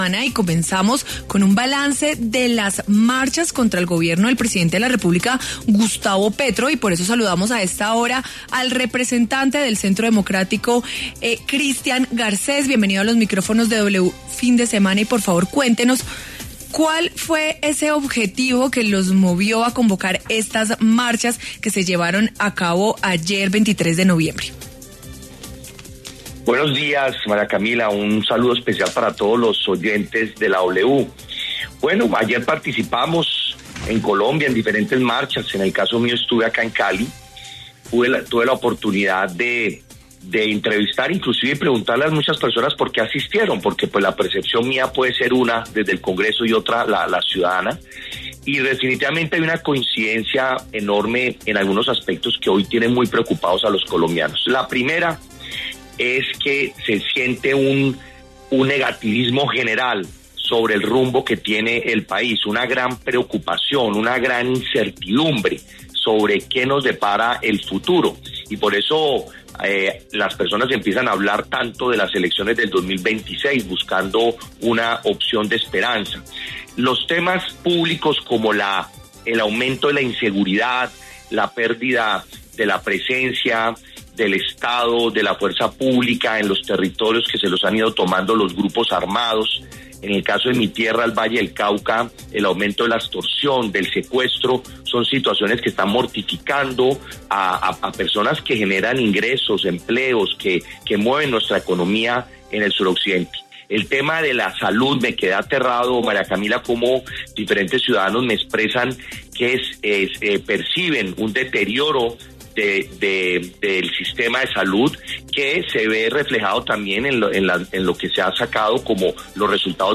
El representante del Centro Democrático, Christian Garcés, pasó por los micrófonos de W Fin de Semana entregando un balance de la jornada de movilizaciones a lo largo y ancho del país.